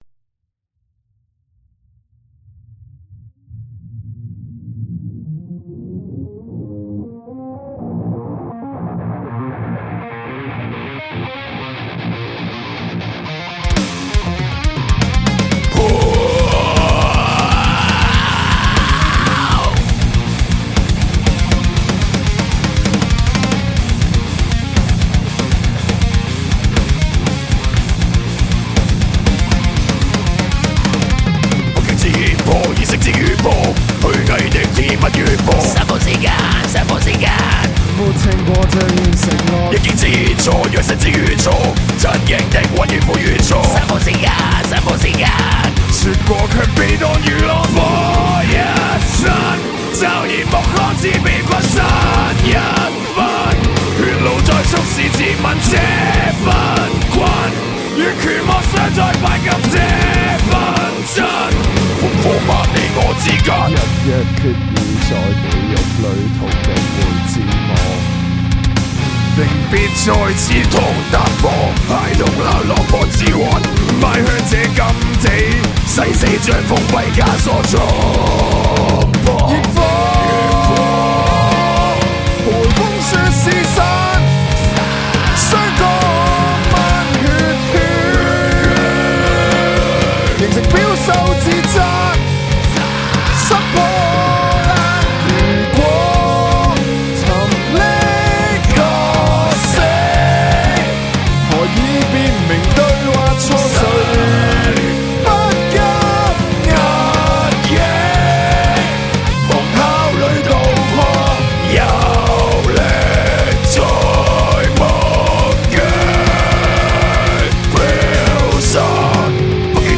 Heavy riffs of metal music were therefore put in the music with constant hip-hop groovy patterns to embody the intricate emotion. Meanwhile, the multiple effects on electric guitar sound also enriched the power of the music.